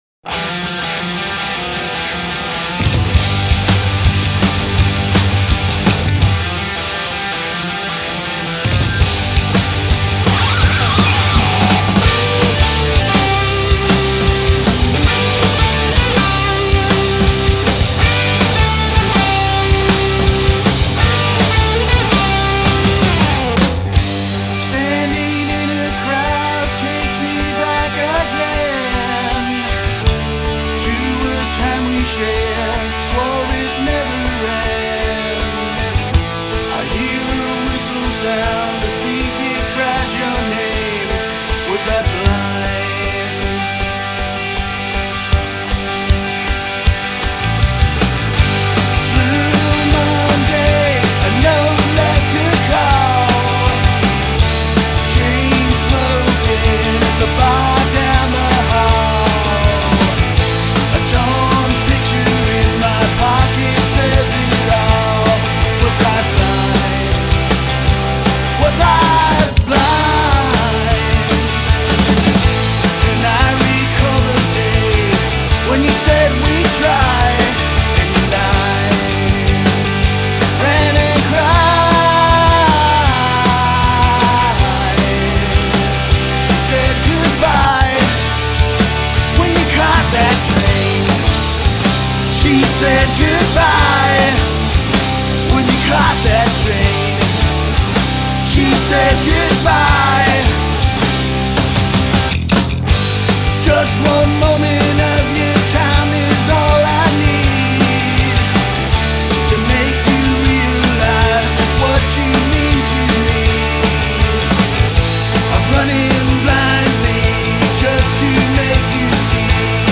Guitar & Vocal
Drums
Bass & Vocal
Recordsed at Tru One Studios